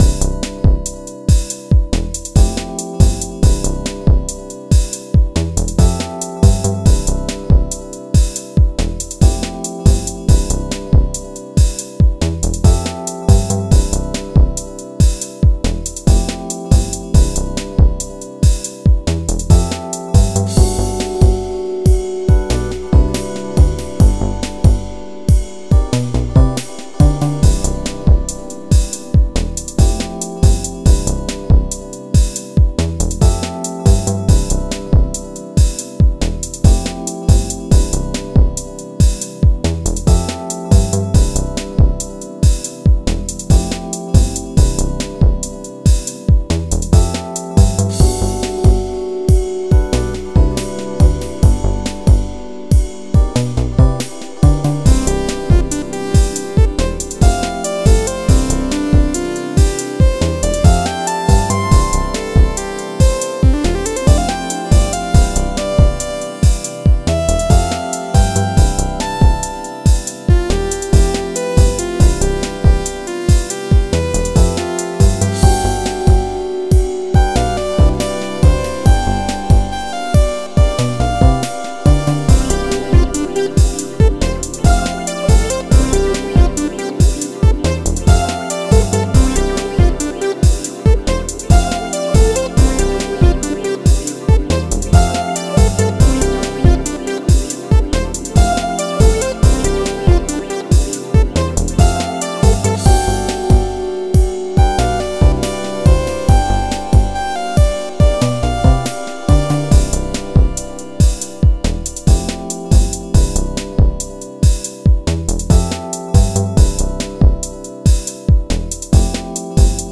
Genre Pop